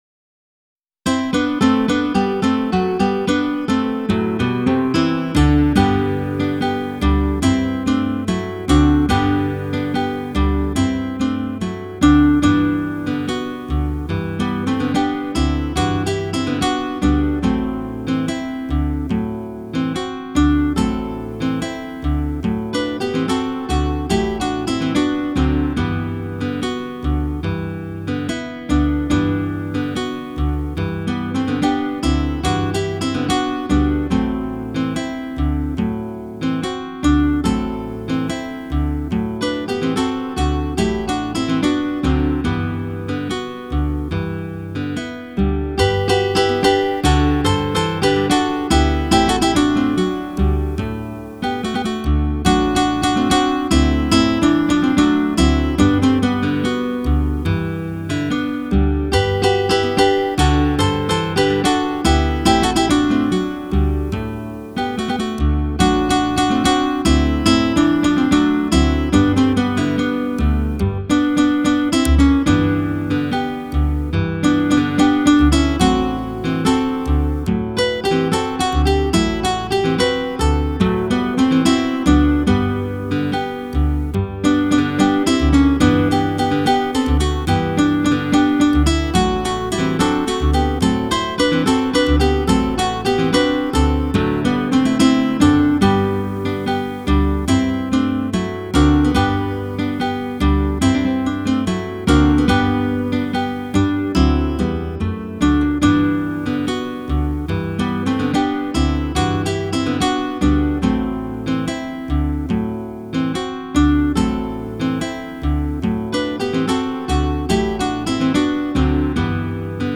für 4 Gitarren (Bass ad libitum)